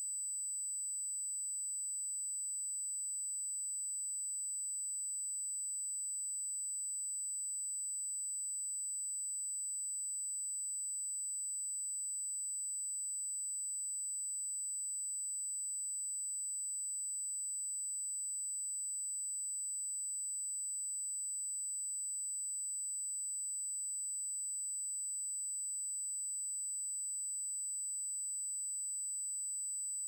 8k.wav